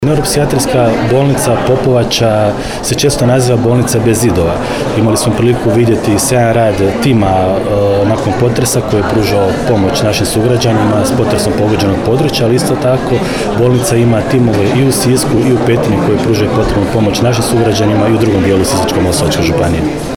U povodu obilježavanja 90 godina Neuropsihijatrijske bolnice „dr. Ivan Barbot” Popovača u petak, 22. studenog 2024. godine, u Domu kulture u Popovači održana je prigodna svečanost.
Župan dodaje